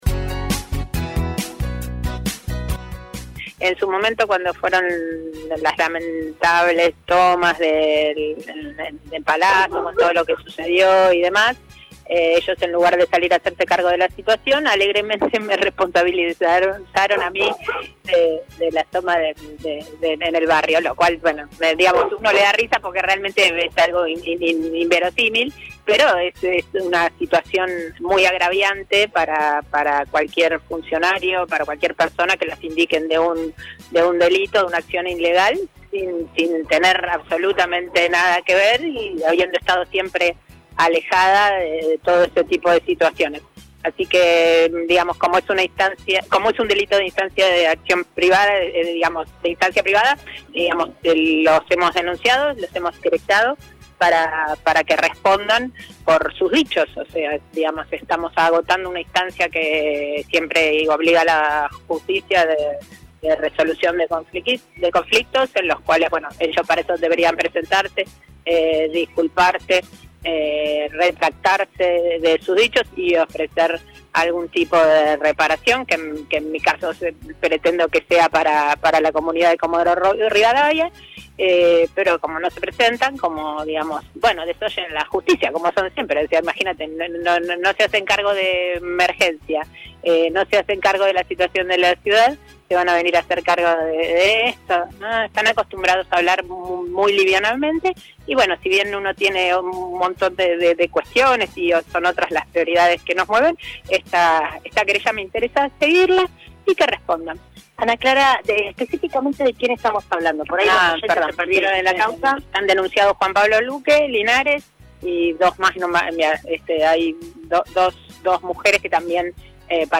Convocada a una audiencia junto a Juan Pablo Luque, la diputada nacional Ana Clara Romero explicó a los micrófonos de RADIOVISIÓN los motivos de esta citación: